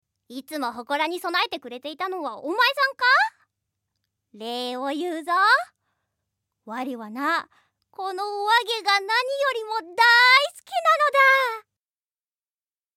Voice
セリフ2